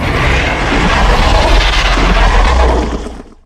File:Mantleclaw roar.ogg